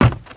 HauntedBloodlines/STEPS Wood, Creaky, Run 09.wav at main
STEPS Wood, Creaky, Run 09.wav